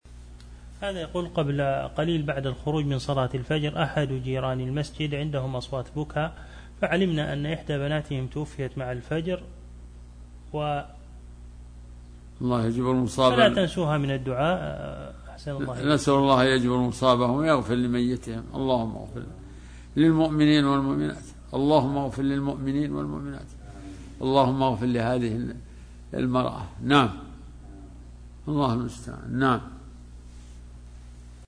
دعاء الشيخ لأموات المسلمين.